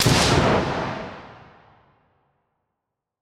大雷击.mp3